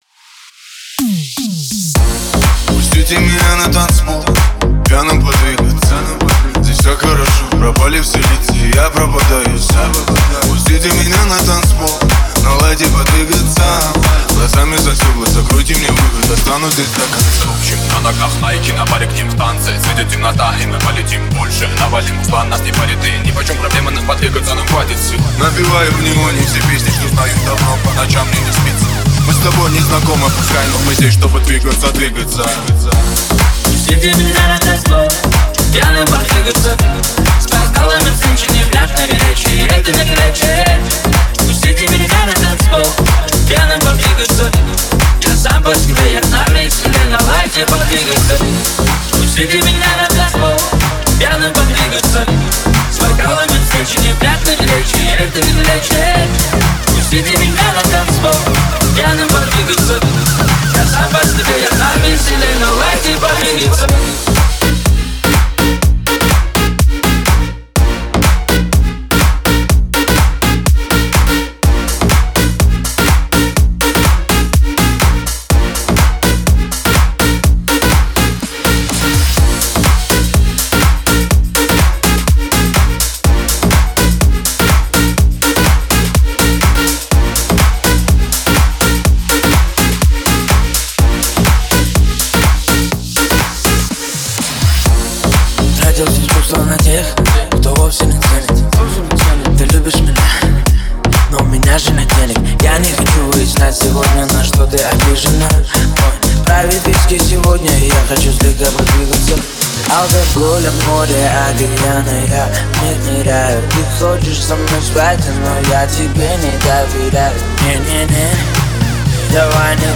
Звучание сочетает в себе динамичные биты и мелодичные вокалы